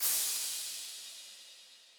TBP - Industrial Crash.wav